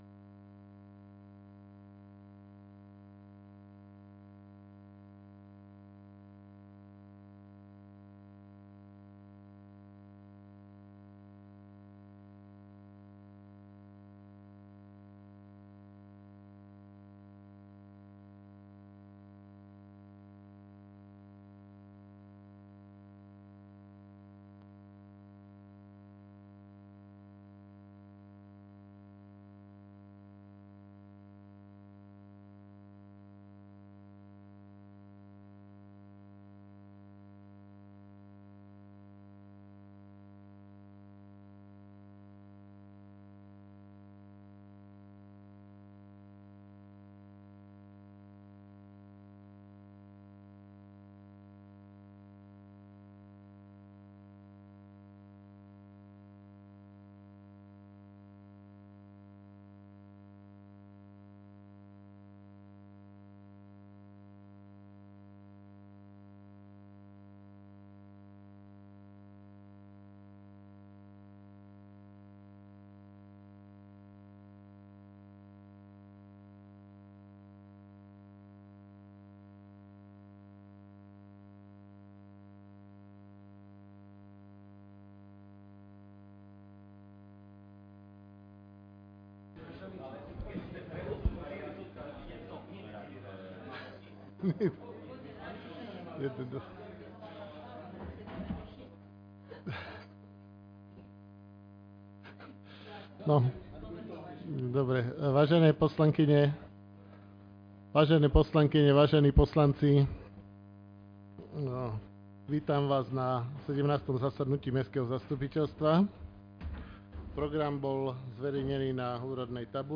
17. zasadnutie Mestského zastupiteľstva v Levoči